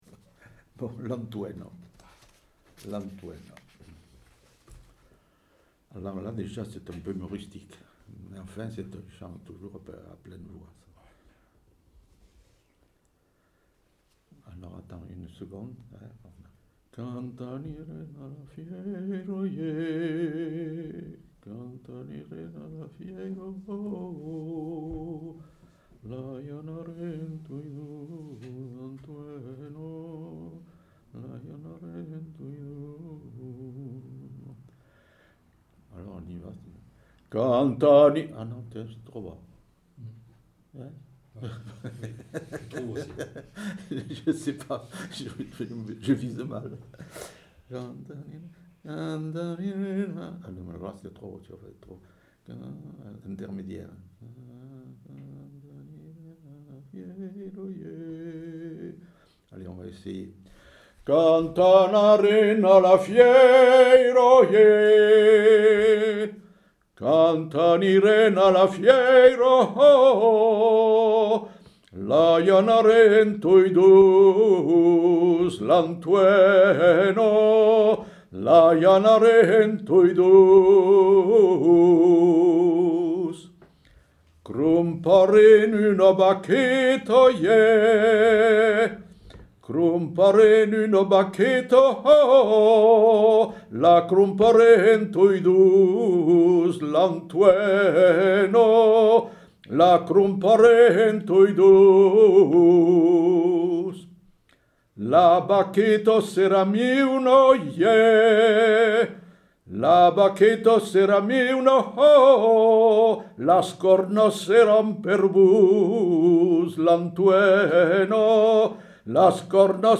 Aire culturelle : Rouergue
Lieu : Saint-Sauveur
Genre : chant
Effectif : 1
Type de voix : voix d'homme
Production du son : chanté
Notes consultables : L'interprète s'arrête au 5ème couplet et reprend.